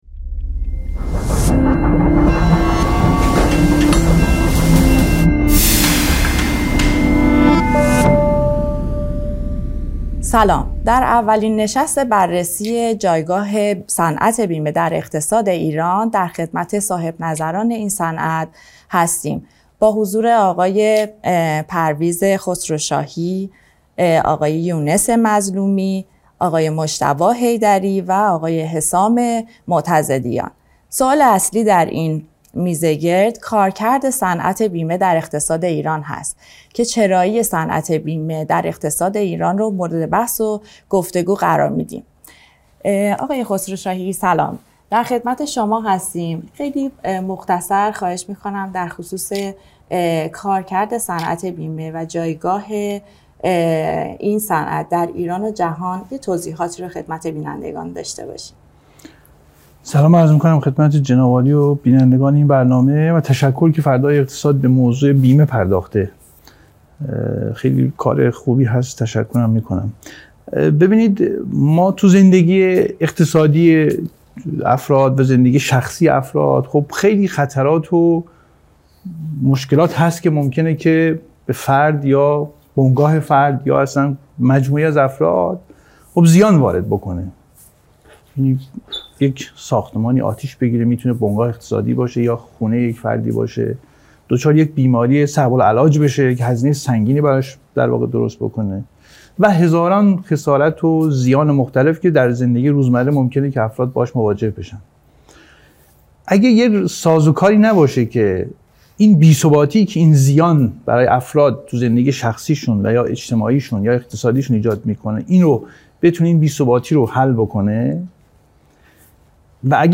جایگاه صنعت بیمه در اقتصاد ایران و جهان موضوع اولین نشست تحلیلی این صنعت در استودیوی فردای اقتصاد بود. به اعتقاد صاحبنظران، ۵۰درصد ریسک‌های کشور پوشش بیمه‌ای ندارد.